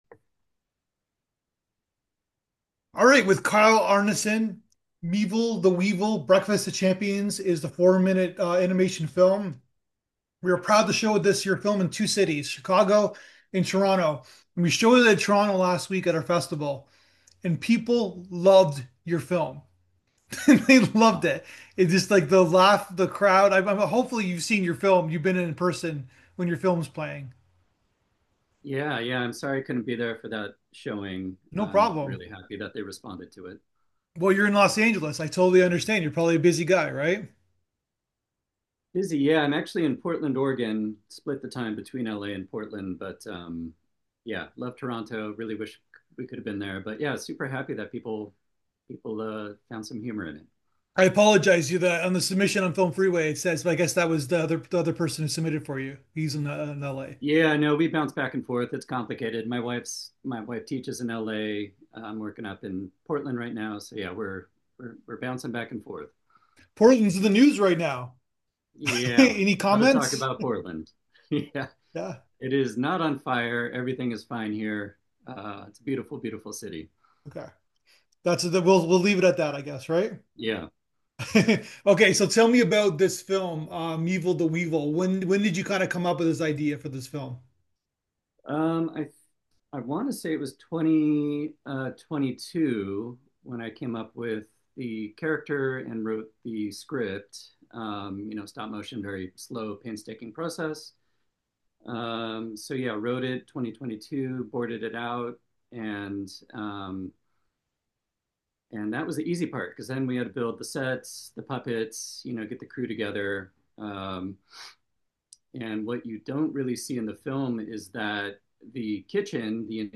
Conversations with film professionals and great storytelling moments.